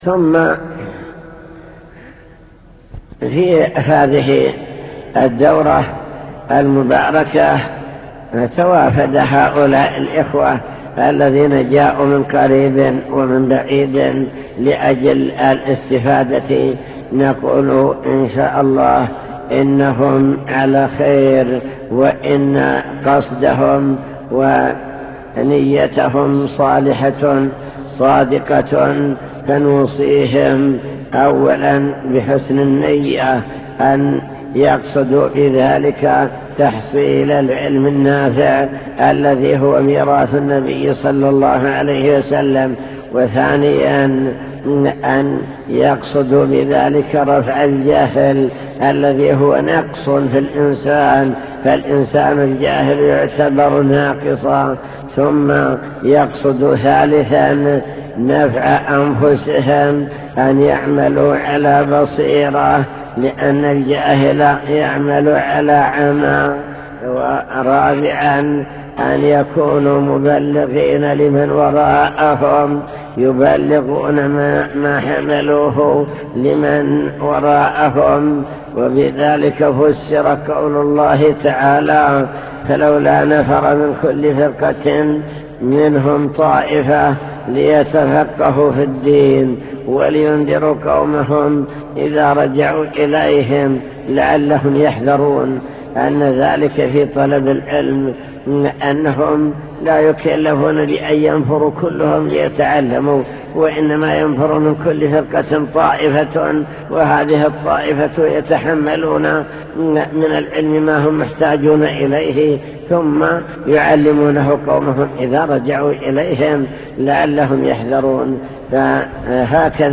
المكتبة الصوتية  تسجيلات - لقاءات  لقاء مفتوح مع الشيخ